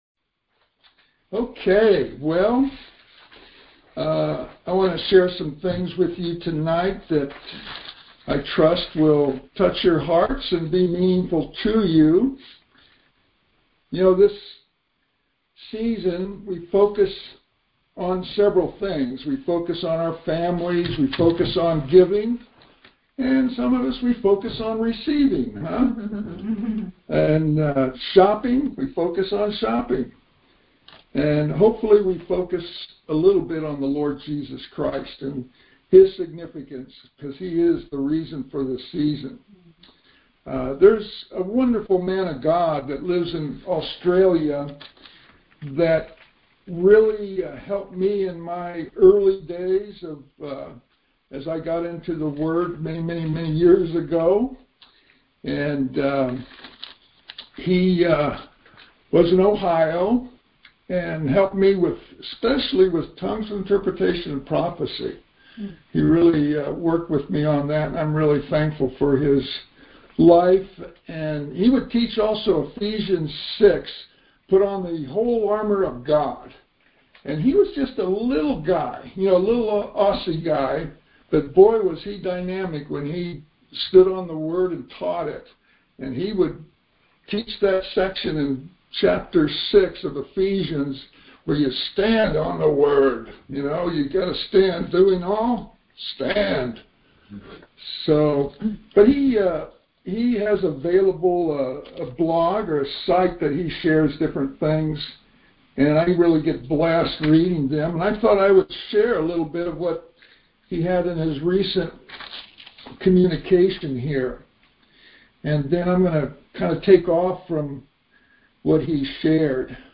Conference Call Fellowship